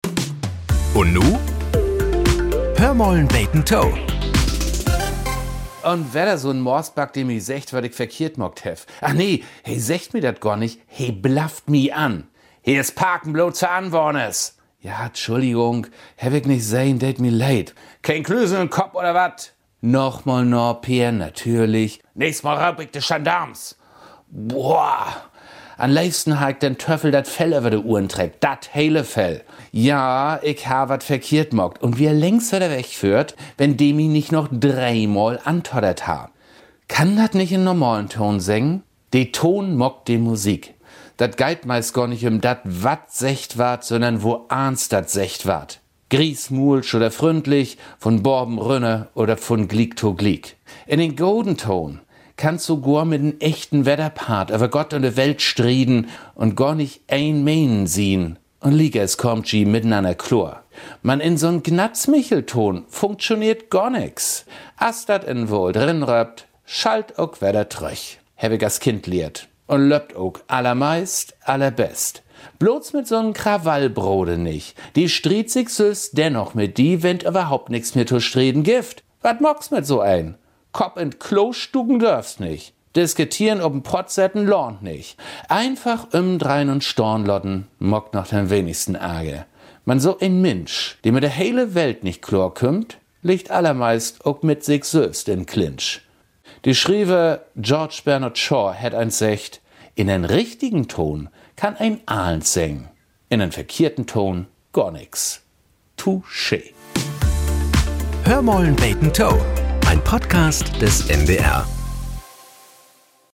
Nachrichten - 26.02.2025